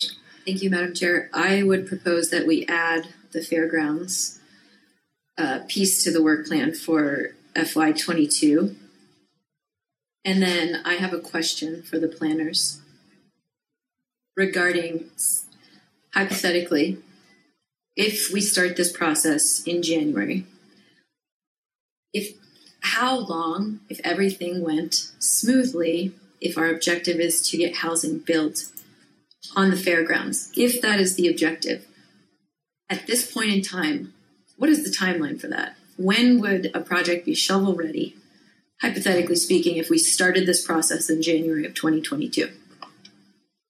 Jackson-Town-Council-Audio.mp3